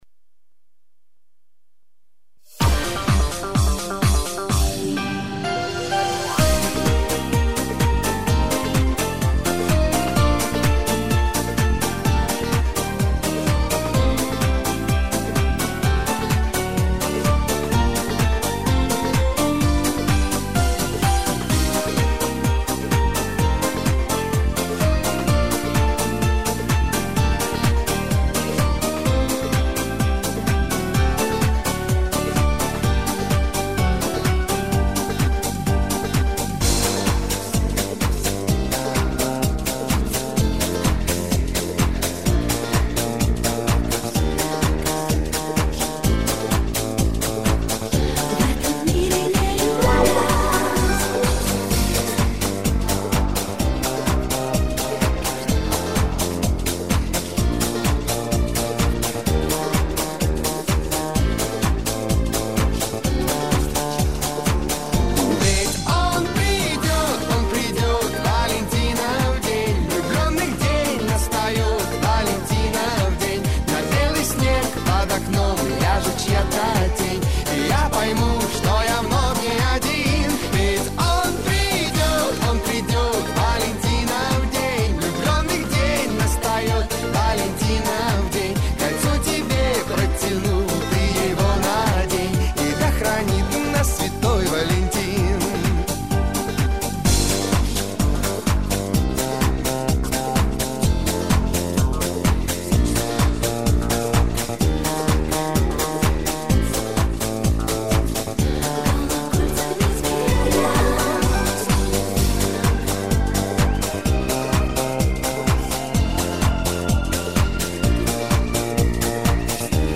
Качество:Задавка